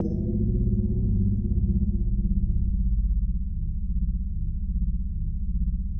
科幻无人机 " 无人机03
描述：科幻无人机，用于室内或室外房间的音调，气氛，外星人的声音，恐怖/期待的场景.
Tag: 外来 大气压 无人驾驶飞机 音响 恐怖 roomtones SCI